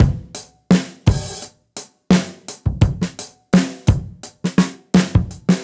Index of /musicradar/dusty-funk-samples/Beats/85bpm
DF_BeatC_85-01.wav